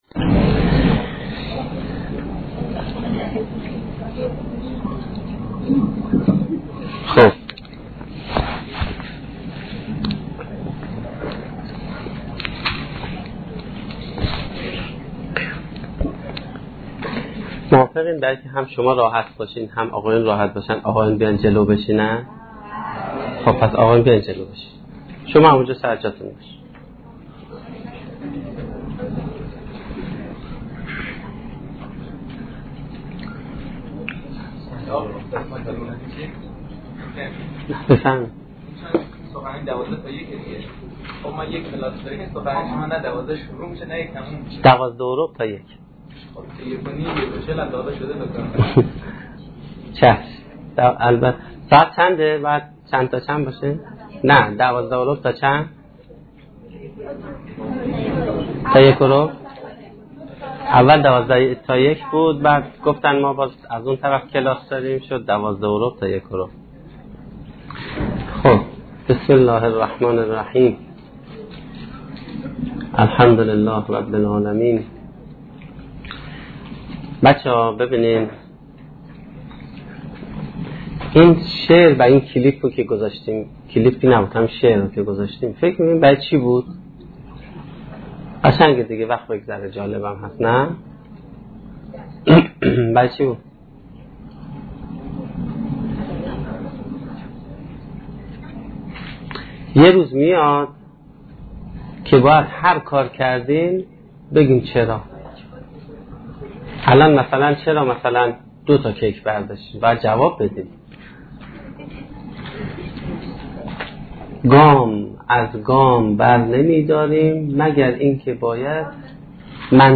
سخنرانی
در دانشگاه فردوسی با موضوع طراحی سرنوشت